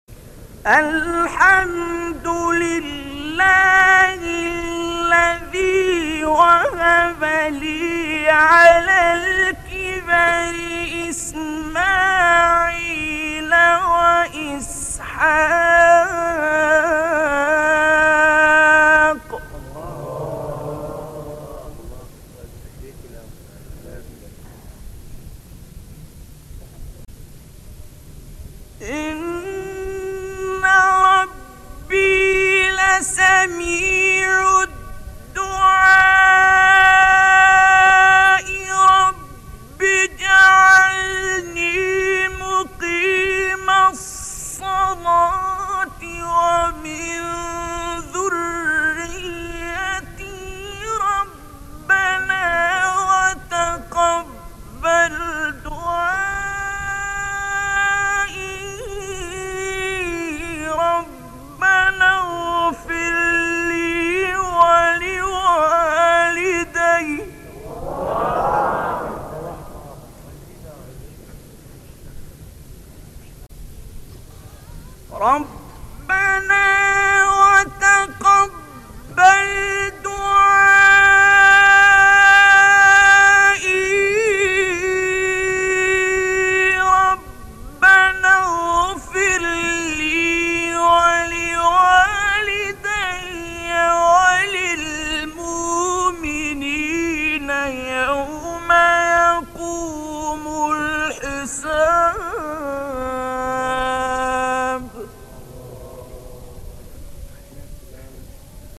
گروه شبکه اجتماعی: فرازهایی از تلاوت قاریان ممتاز مصری در زیر ارائه می‌شود.